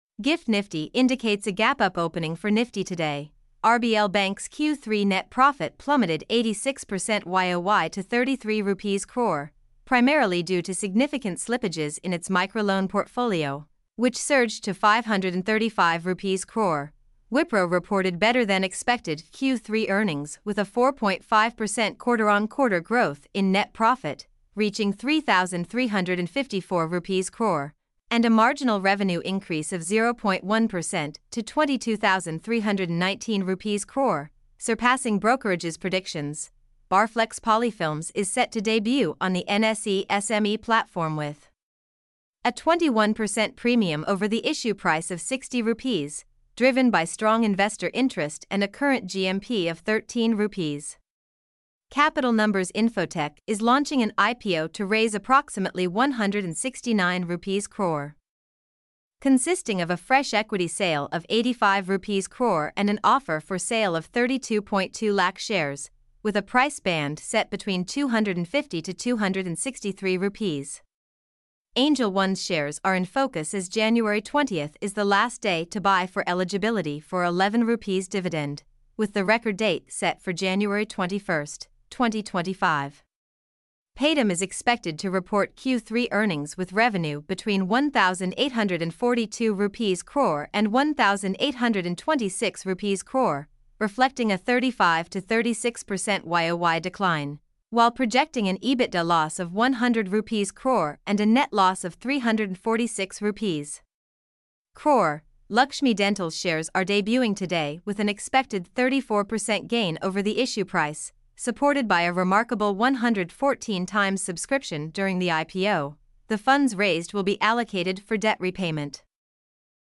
mp3-output-ttsfreedotcom7.mp3